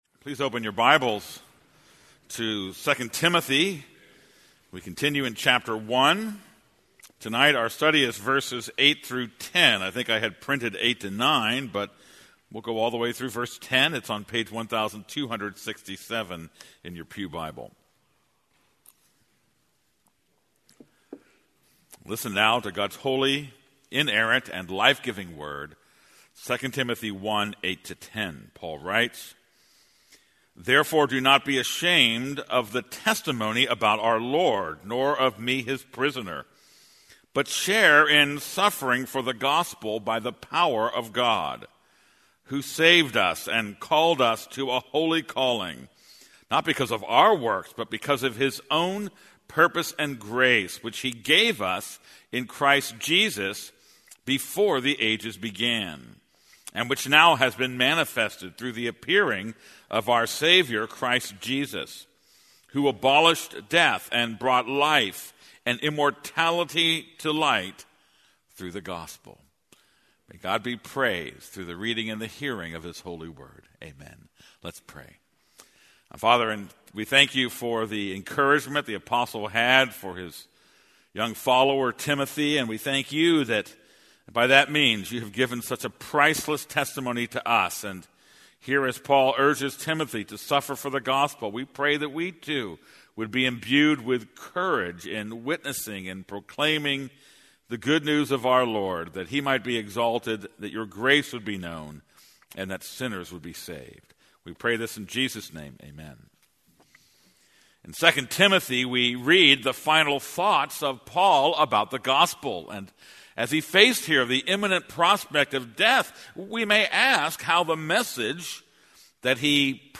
This is a sermon on 2 Timothy 1:8-9.